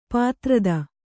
♪ pātrada